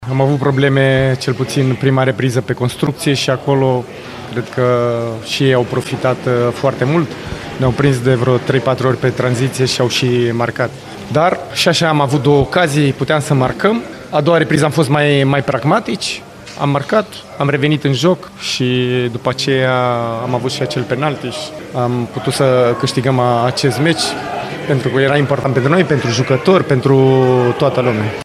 Și omologul său Constantin Gâlcă a enumerat problemele de exprimare din prima parte, urmate de reușitele ce au adus victoria: